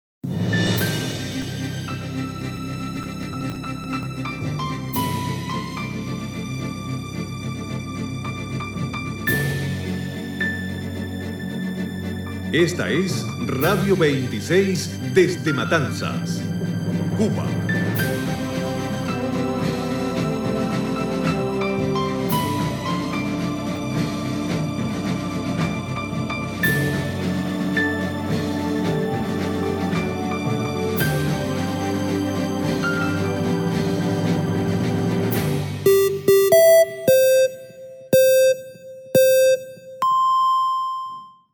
Cuba AM Radio Station Identification
Radio 26 - ID with signature piano theme music and time signal.